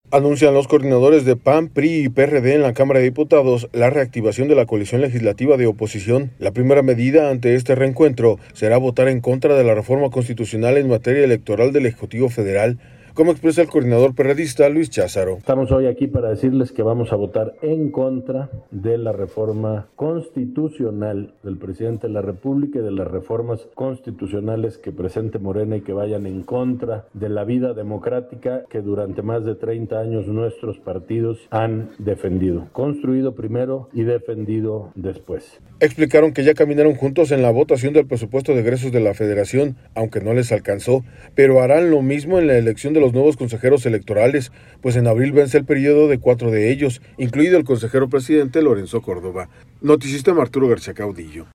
audio Anuncian los coordinadores de PAN, PRI y PRD en la Cámara de Diputados la reactivación de la coalición legislativa de oposición. La primera medida, ante este reencuentro, será votar en contra de la Reforma Constitucional en materia electoral del Ejecutivo Federal, como expresa el coordinador perredista, Luis Cházaro.